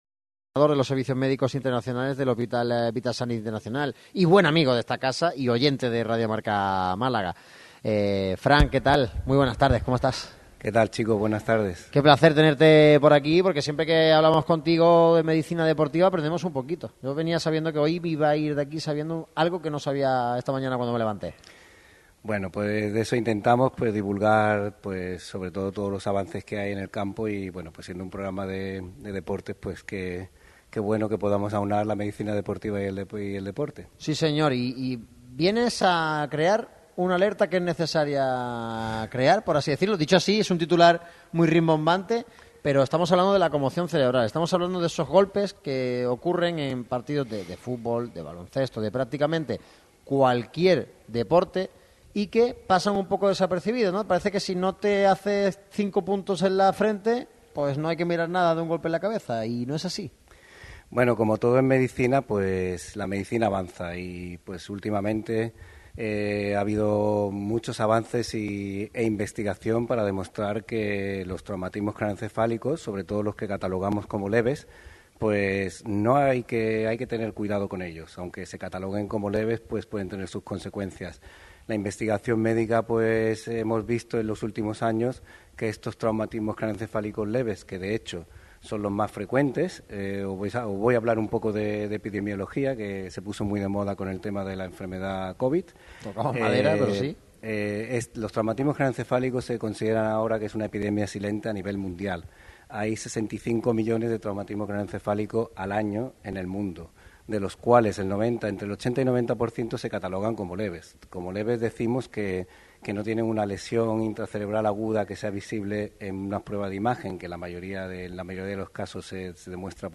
El micrófono rojo de Radio MARCA Málaga se desplaza hoy a las instalaciones de Vithas Xanit Salud en Av. de los Argonautas, en la localidad malagueña de Benalmádena. Toca hablar de salud pero también de la evolución, crecimiento y gran experiencia que atesora un gigante como el grupo Vithas.